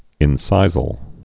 (ĭn-sīzəl)